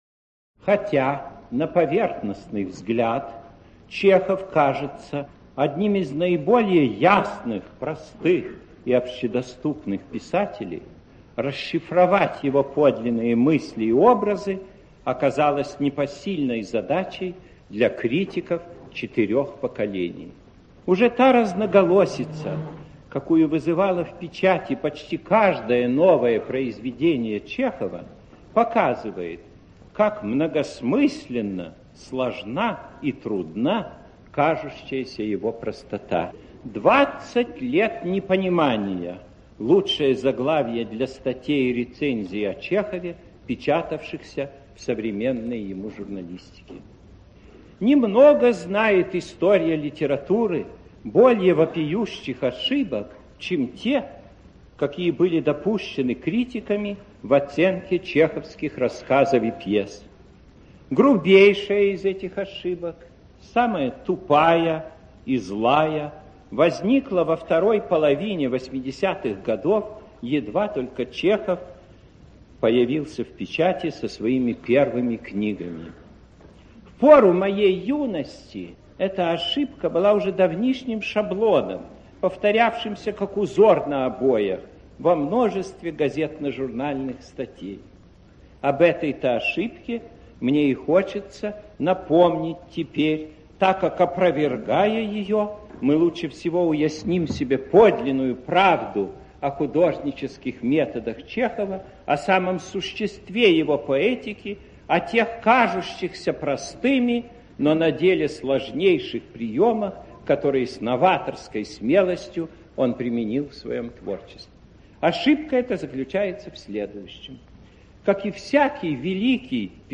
Аудиокнига О Чехове, Некрасове, Репине, Блоке, Пастернаке, Ахматовой, Маяковском, Куприне, Андрееве | Библиотека аудиокниг